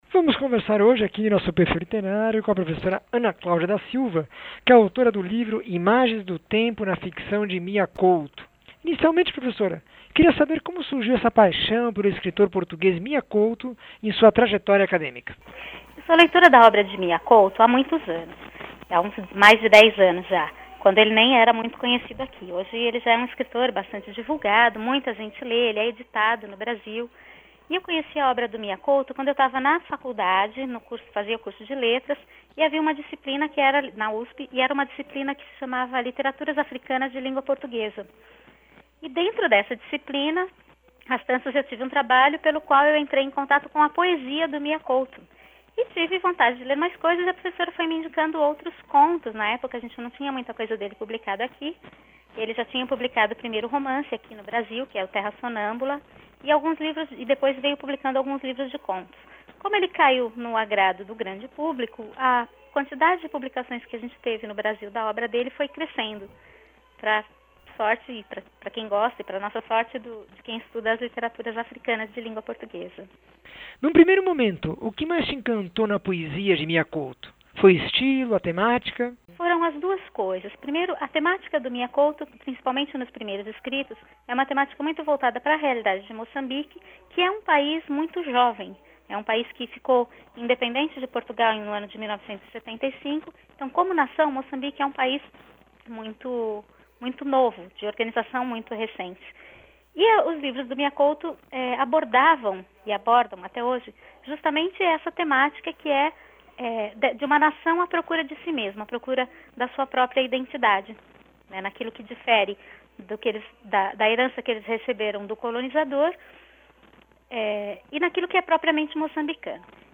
entrevista 1102